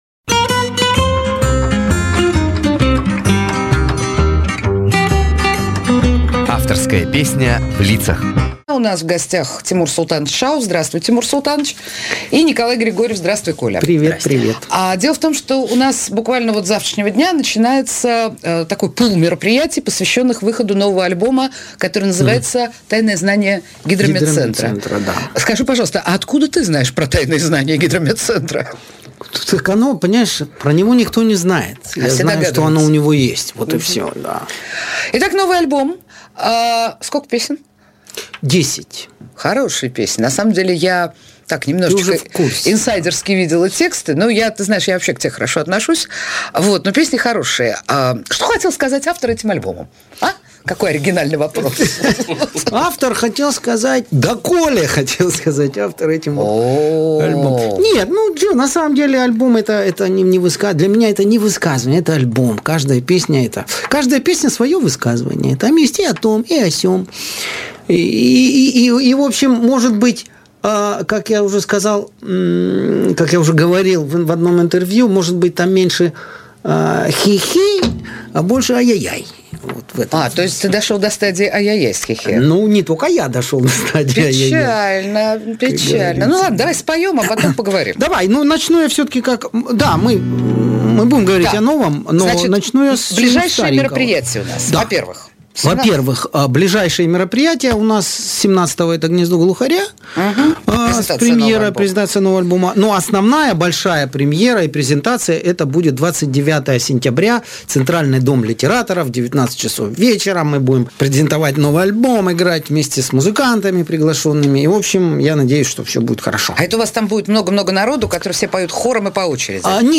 живой звук Жанр: Авторская песня